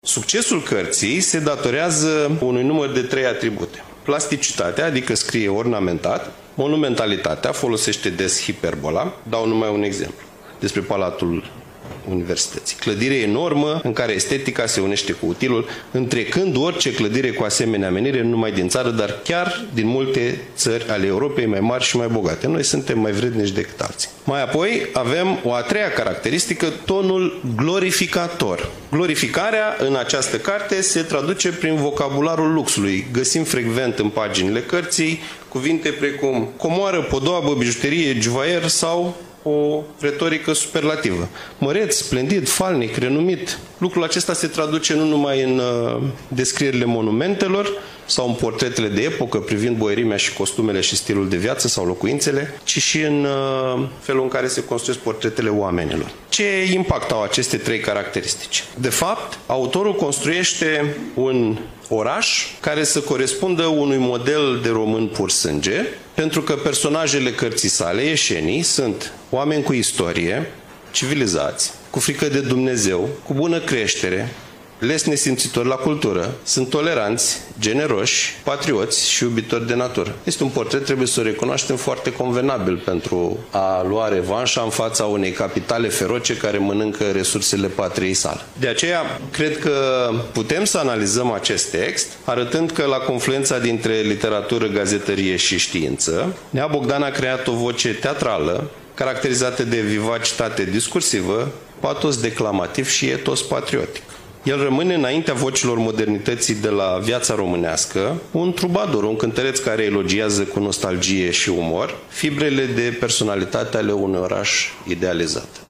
Prezentarea s-a desfășurat în incinta Muzeului Municipal „Regina Maria”, str. Zmeu, nr. 3.